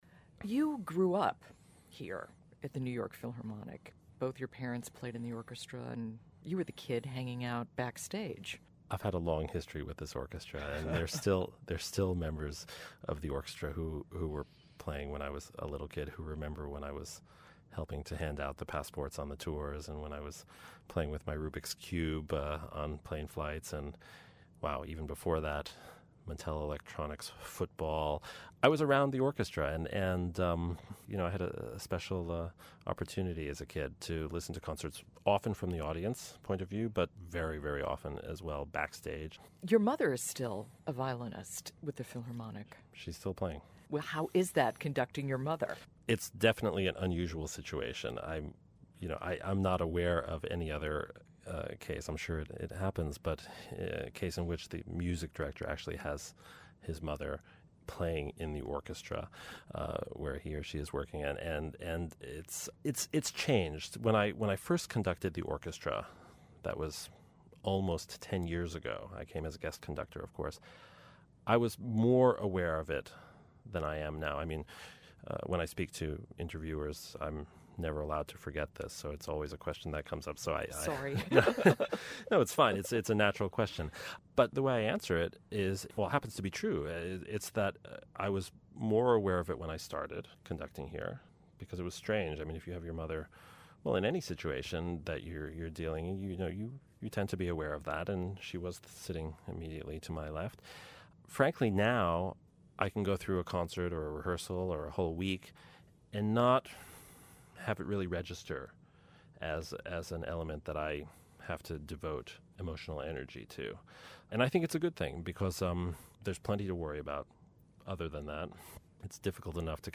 Transcript of interview with Alan Gilbert